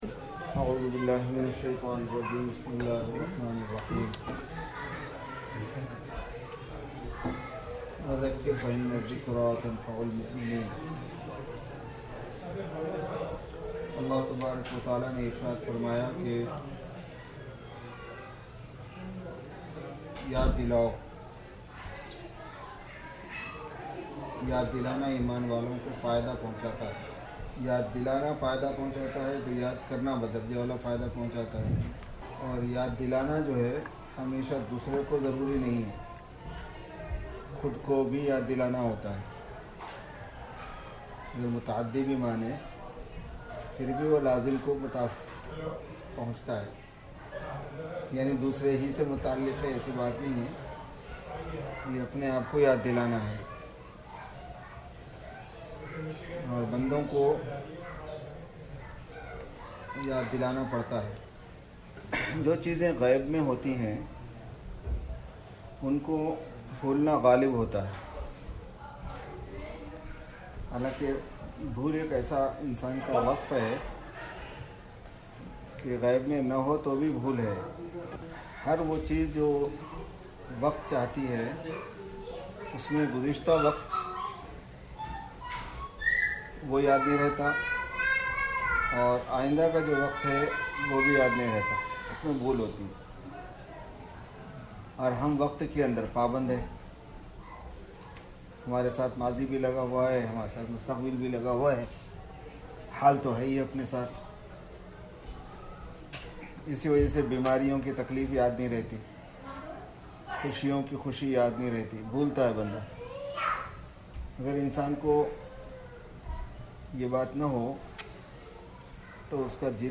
(Jum'ah Bayan)